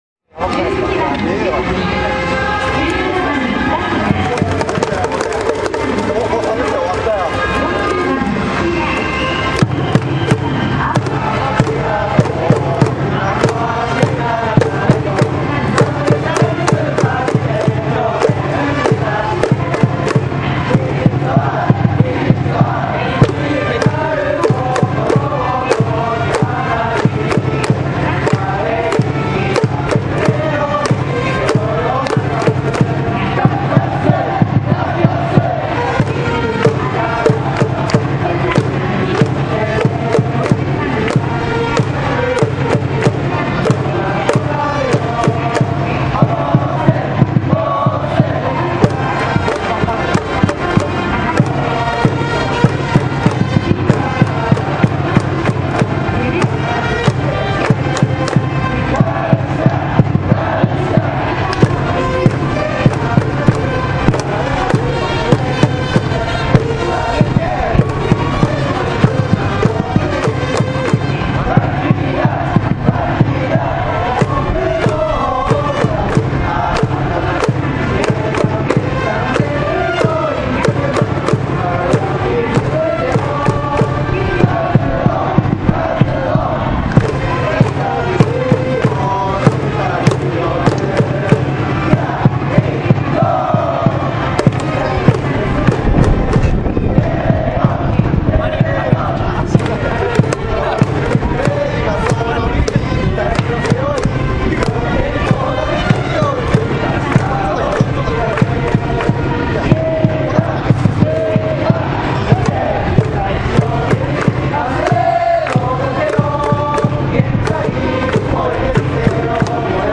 スタメン発表  ＱＶＣマリン ４月１６日  スタメン発表。